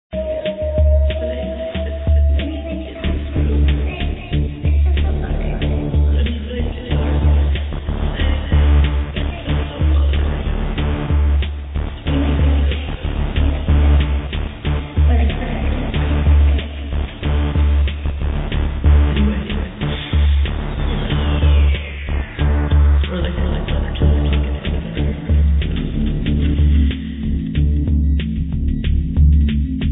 muzyka elektroniczna, ambient
electronic music